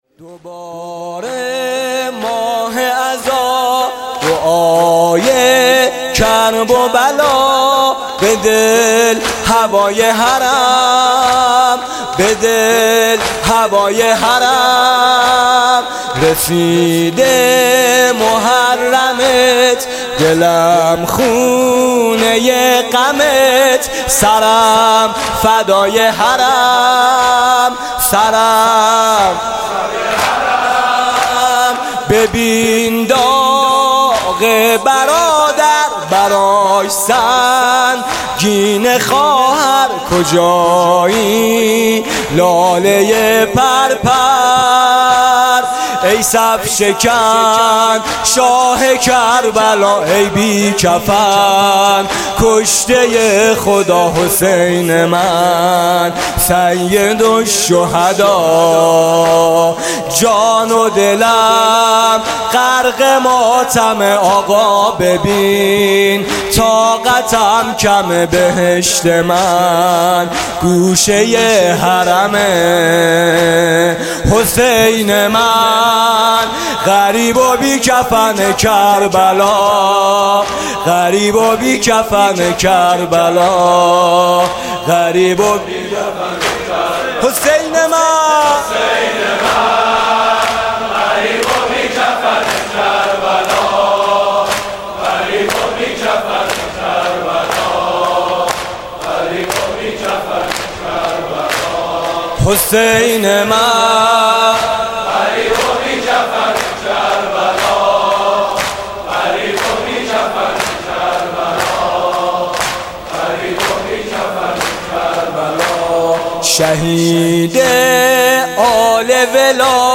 صوت مراسم شب دوم محرم ۱۴۳۷ هیئت میثاق با شهدا ذیلاً می‌آید: